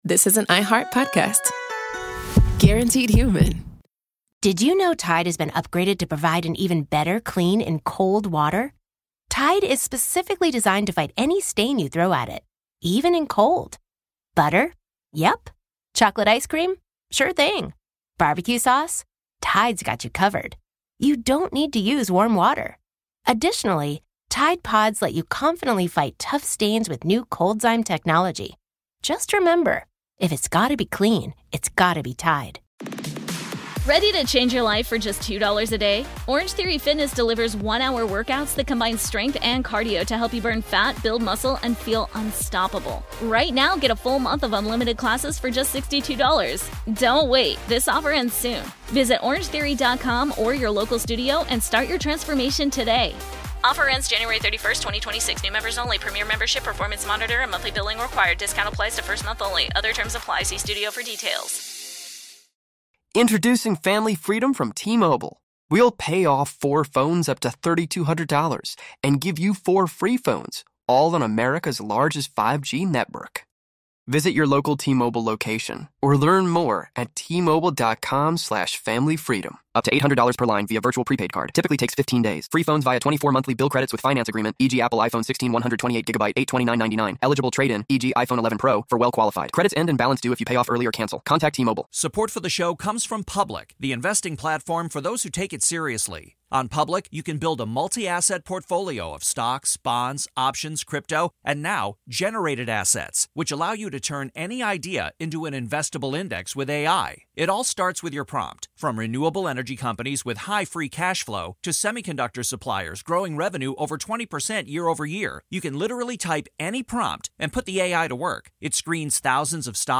Sports historian